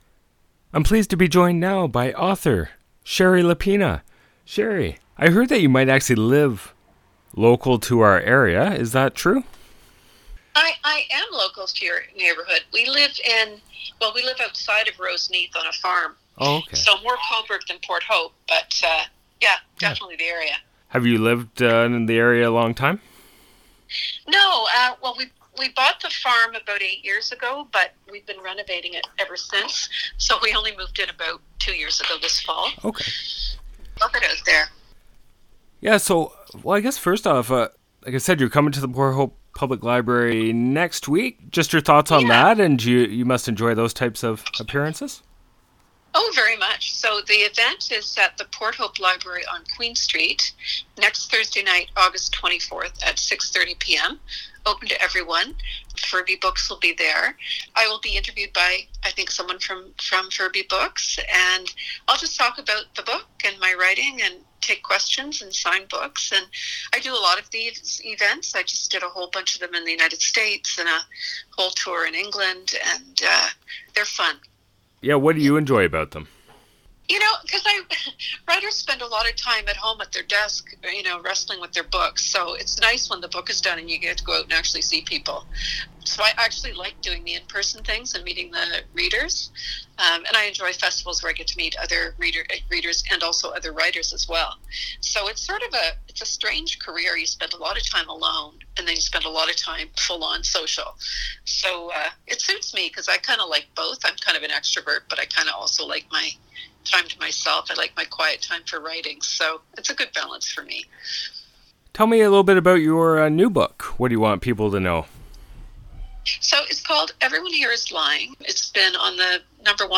ShariLapena-author-interview.mp3